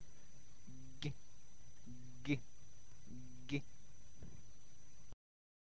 Ã_ã - letter like English g, but this letter is more tender.